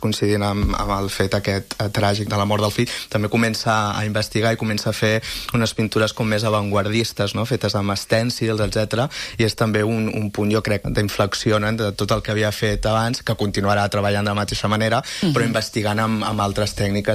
en una entrevista a l’FM i +.